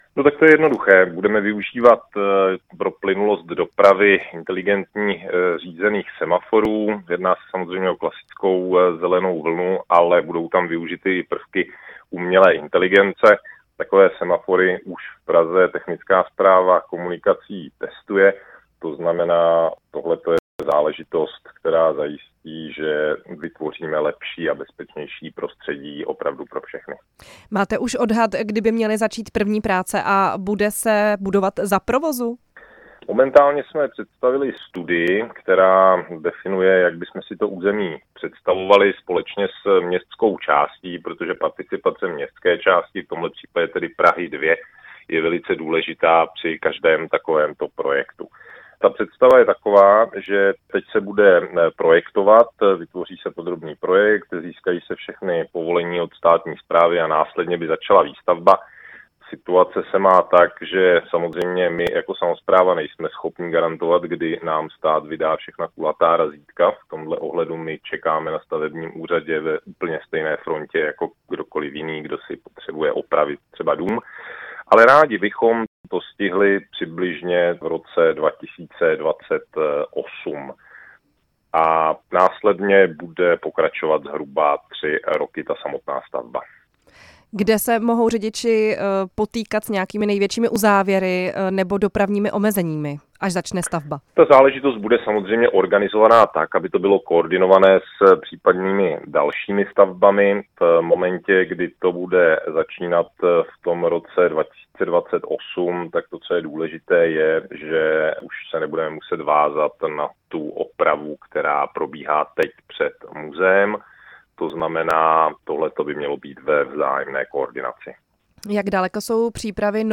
Ptali jsme se náměstka pražského primátora Zdeňka Hřiba z Pirátské strany.
Zdeněk Hřib ve vysílání Radia Prostor